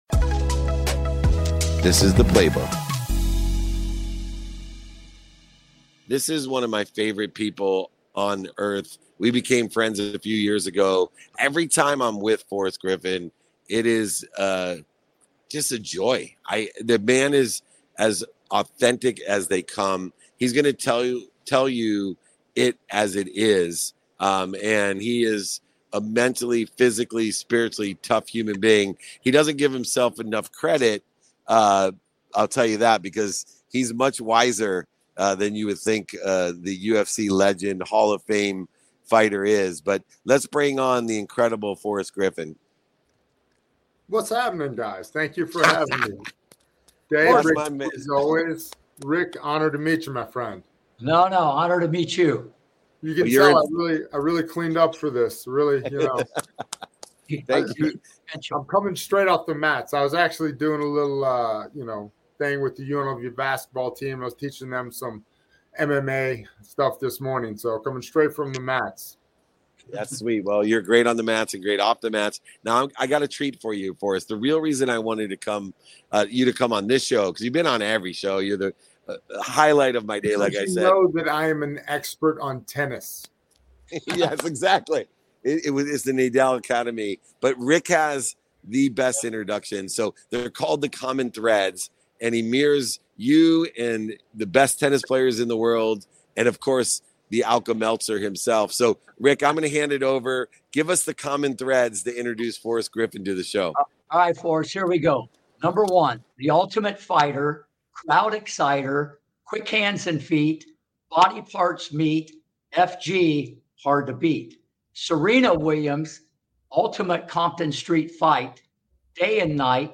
David Meltzer and Rick Macci sit down with UFC legend Forrest Griffin to discuss UFC's journey from a backyard sport to a multibillion-dollar entertainment business, and Forrest's own path to becoming a professional fighter. They explore the role of mental toughness, visualization, and consistent practice in shaping athletes, and hint at Forrest's promising future in entertainment. They delve into Forrest's financial struggles and injuries, underscoring his perseverance and resilience.